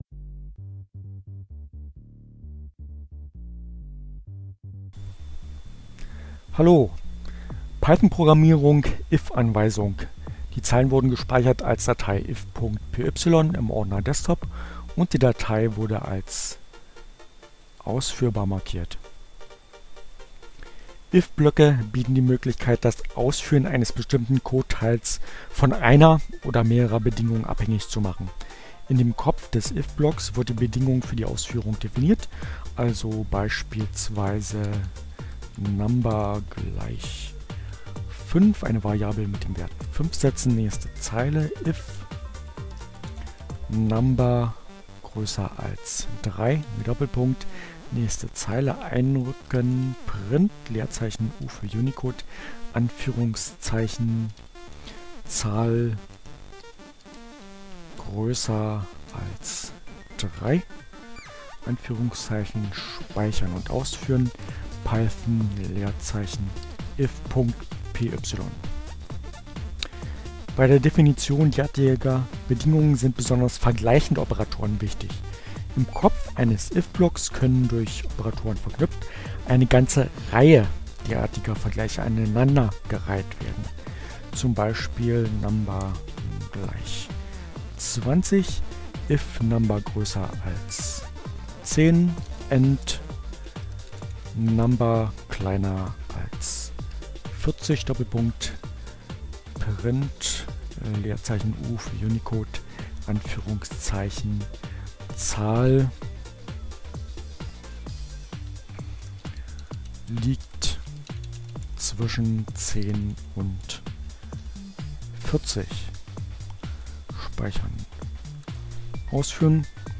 Tags: CC by-sa, Gnome, Linux, Neueinsteiger, Ogg Theora, ohne Musik, screencast, ubuntu, Python, Programmierung